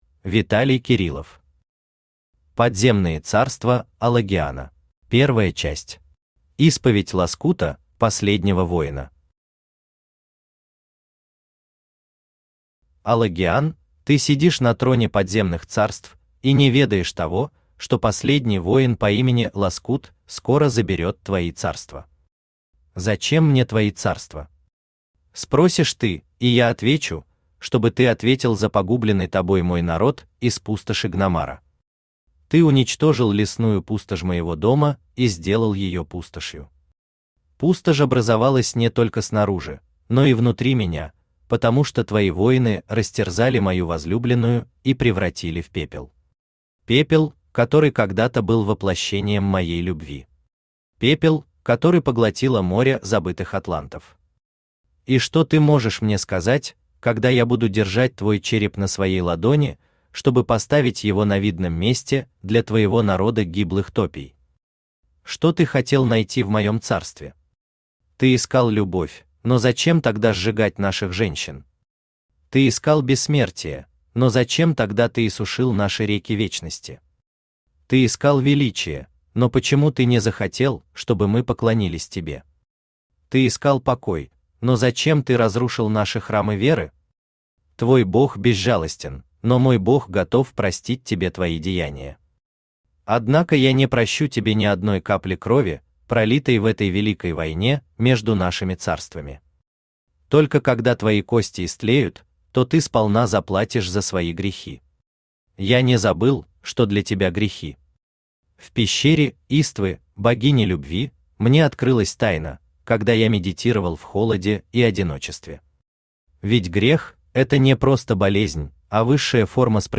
Аудиокнига Подземные царства Алагиана | Библиотека аудиокниг
Aудиокнига Подземные царства Алагиана Автор Виталий Александрович Кириллов Читает аудиокнигу Авточтец ЛитРес.